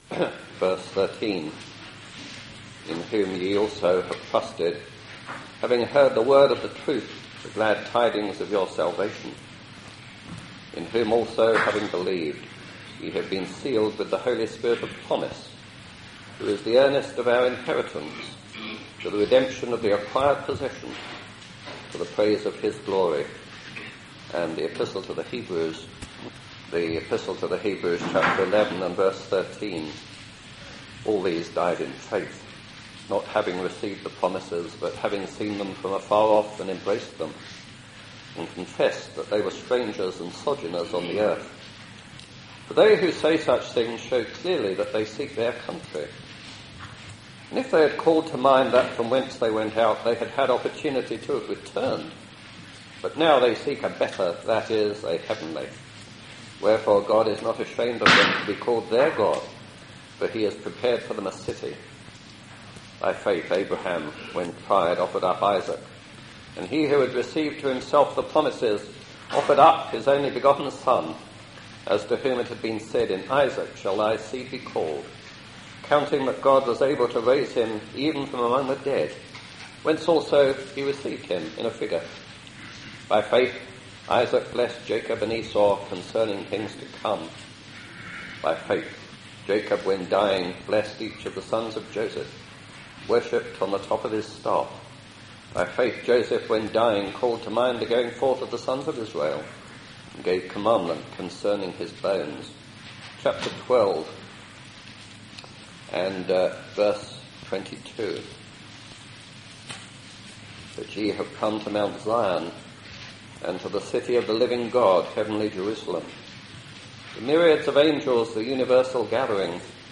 In the following address, you will hear about the things present and the things yet to come.